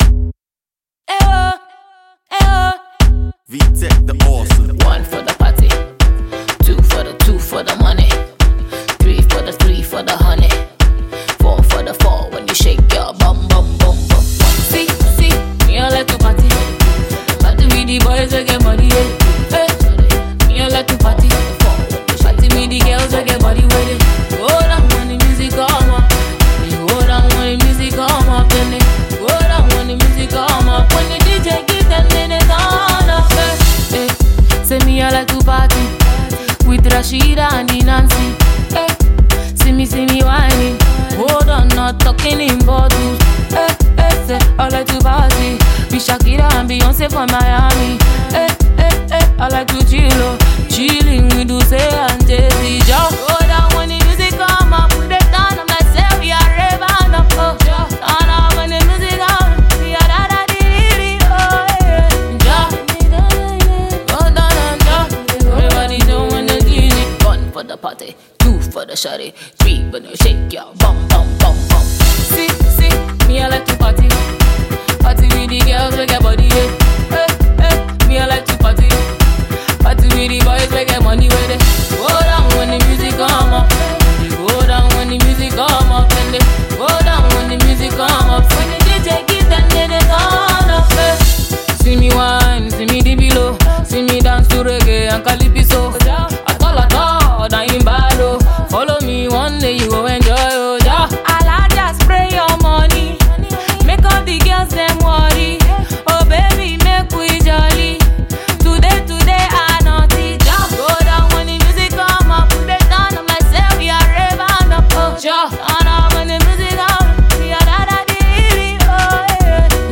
dancehall vibe track